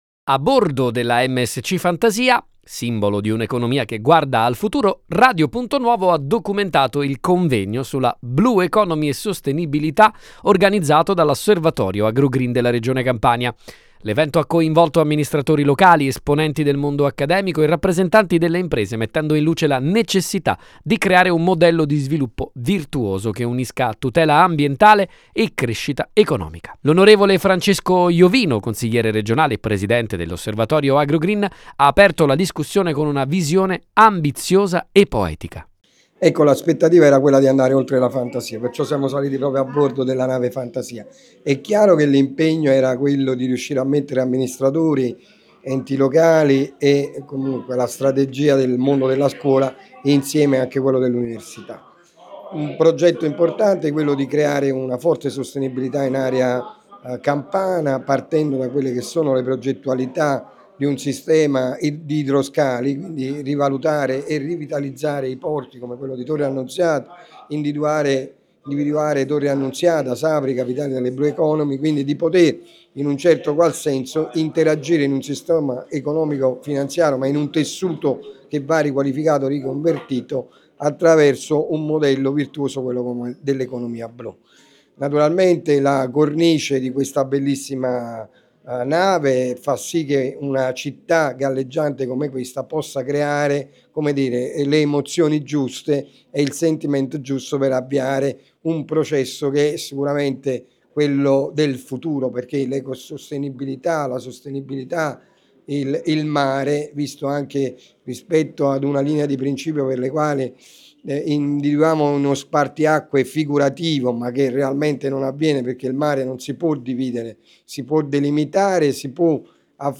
A bordo della MSC Fantasia, simbolo di un’economia che guarda al futuro, Radio Punto Nuovo ha documentato il Convegno sulla Blue Economy e Sostenibilità, organizzato dall’Osservatorio Agro Green della Regione Campania. L’evento ha coinvolto amministratori locali, esponenti del mondo accademico e rappresentanti delle imprese, mettendo in luce la necessità di creare un modello di sviluppo virtuoso che unisca tutela ambientale e crescita economica.